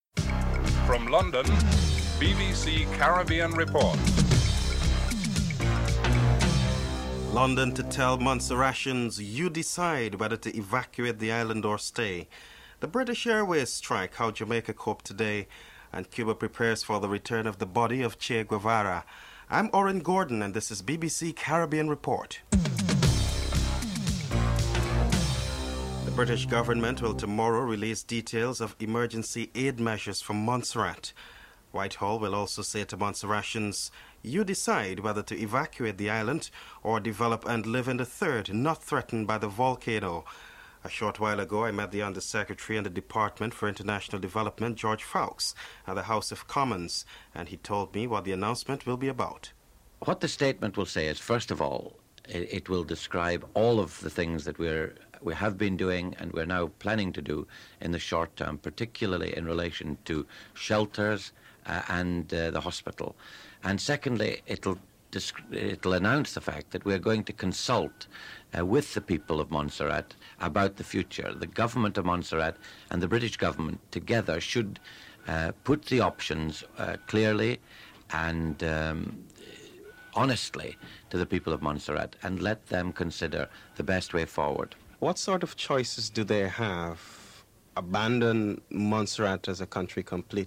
1. Headlines (00:00-00:25)
2. According to the British, Montserrations will decide whether to evacuate the island or stay. Under-Secretary in the Department for International Development, George Foulkes is interviewed (00:26-03:22)